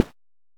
footstep_grass_004.ogg